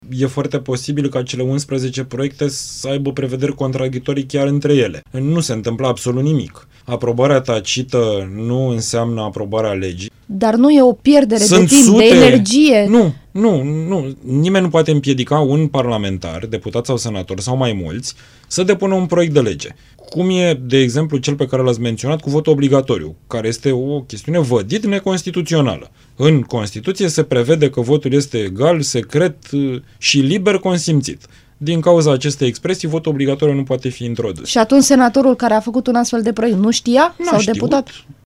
Invitat la Interviurile Europa FM, președintele Comisiei pentru Cod Electoral, deputatul liberal Mihai Voicu, a explicat că STS a testat deja scanerele încă de acum doi ani, iar acestea vor fi folosite la alegerile locale de anul viitor, dacă și Camera Deputaților votează această modificare a legii, aprobată deja de Senat.